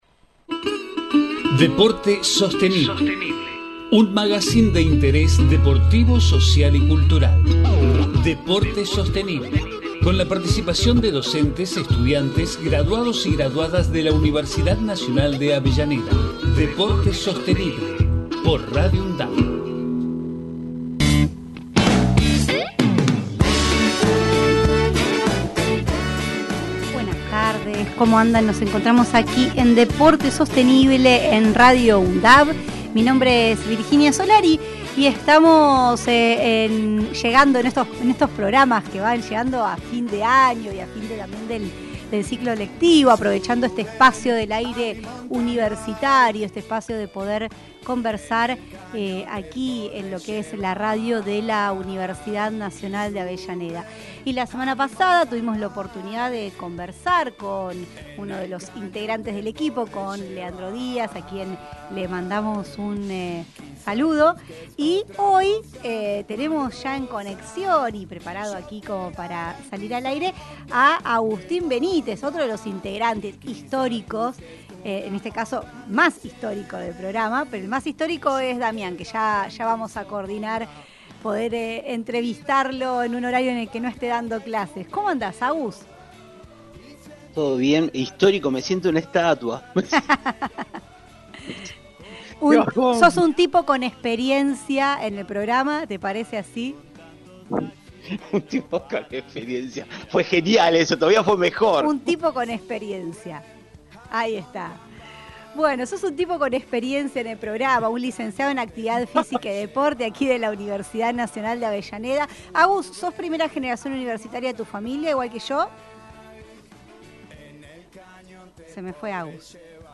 DEPORTE SOSTENIBLE Texto de la nota: En cada programa se busca abordar la vinculación estratégica entre gestión deportiva, desarrollo sostenible, salud, cultura, medio ambiente e inclusión social, realizando entrevistas, columnas especiales, investigaciones e intercambio de saberes. Magazine de interés deportivo, social y cultural que se emite desde septiembre de 2012.